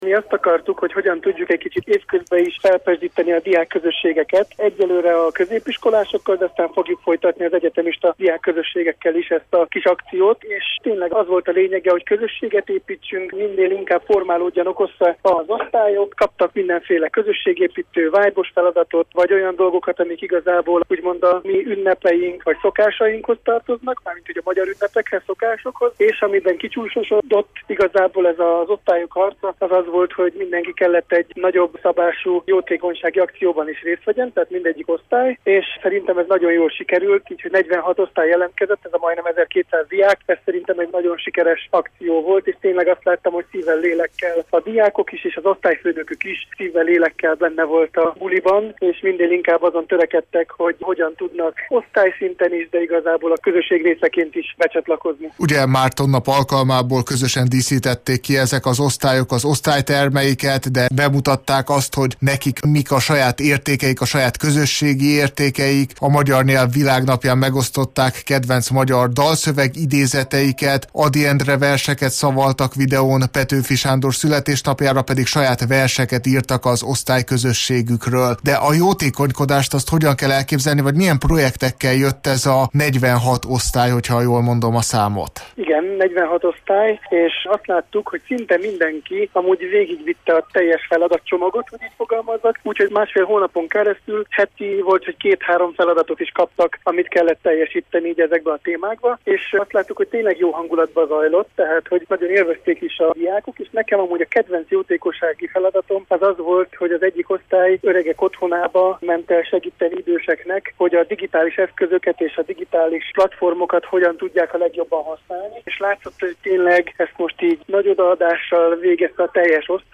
interjúja.